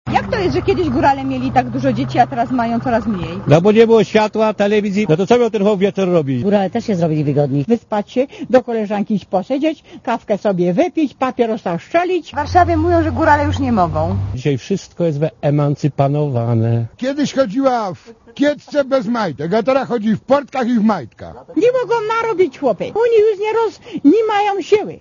Posłuchaj, co o przyroście demograficznym mówią górale (98 KB)
gorale.mp3